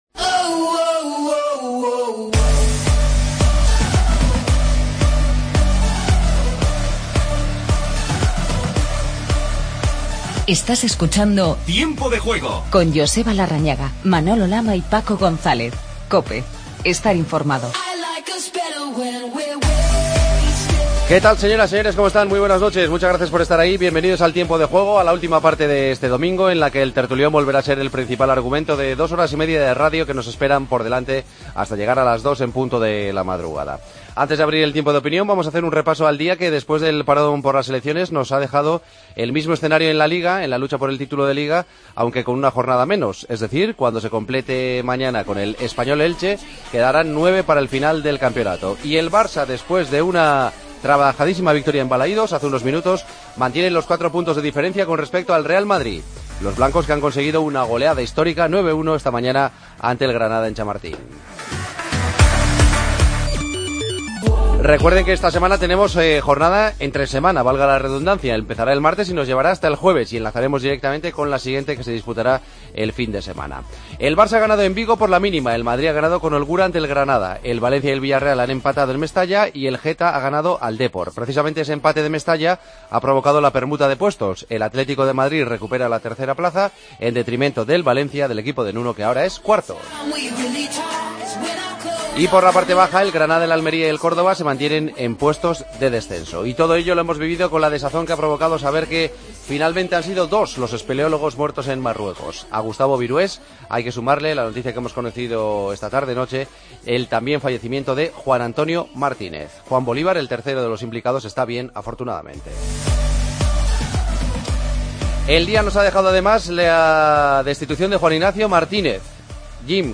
El Barcelona gana al Celta y sigue con cuatro puntos de ventaja sobre el Madrid, que ganño 9-1 al Granada. Entrevista a Nolito y escuchamos a Mathieu.